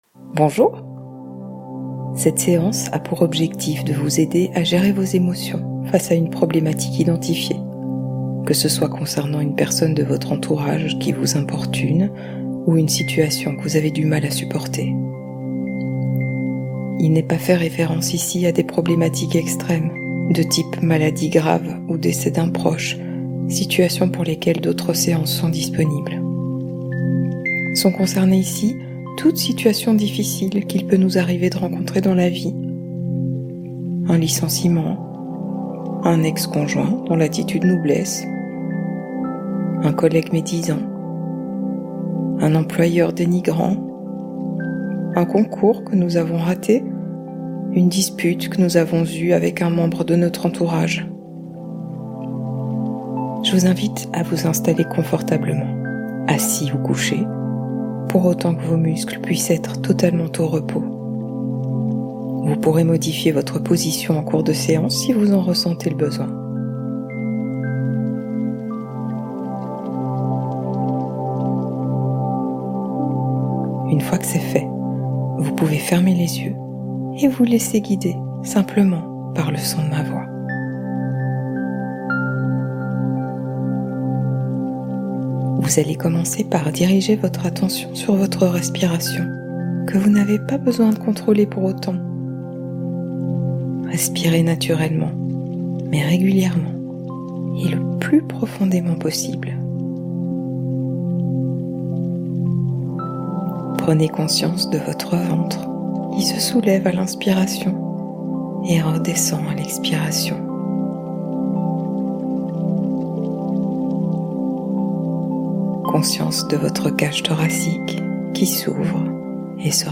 Méditation Guidée